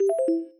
shake.mp3